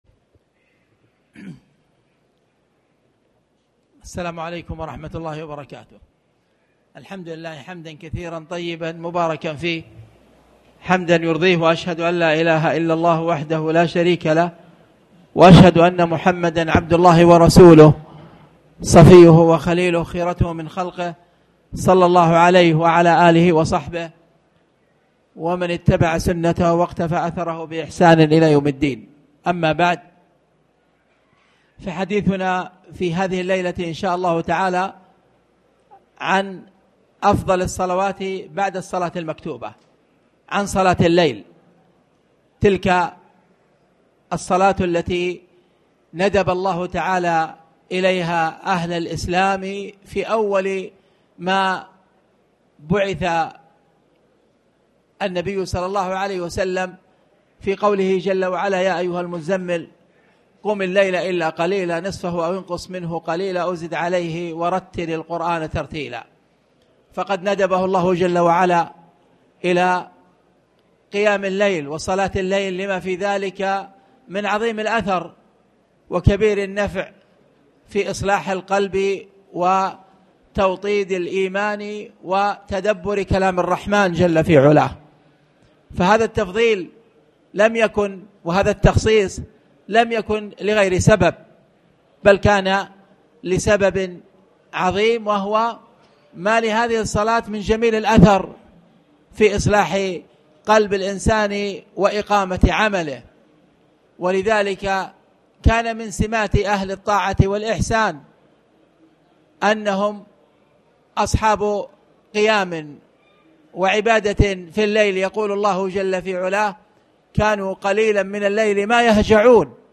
تاريخ النشر ٧ جمادى الآخرة ١٤٣٩ هـ المكان: المسجد الحرام الشيخ